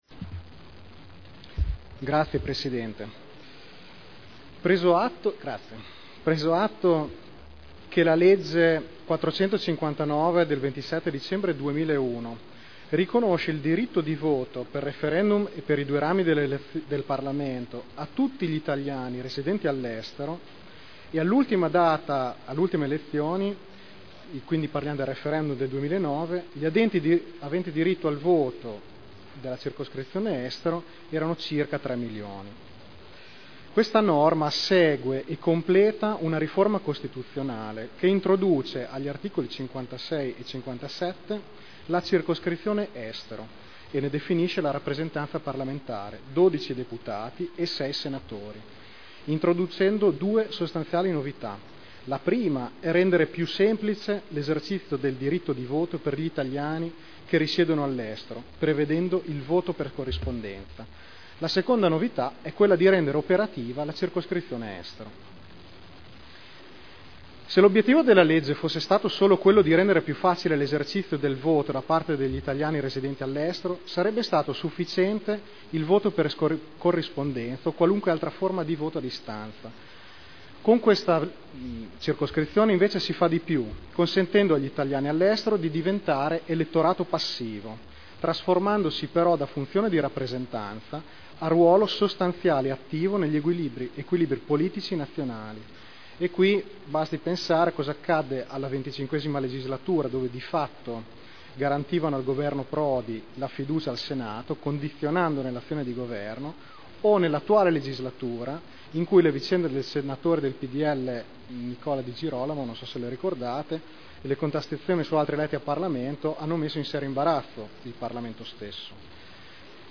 Fabio Rossi — Sito Audio Consiglio Comunale